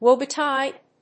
アクセントWóe betíde…!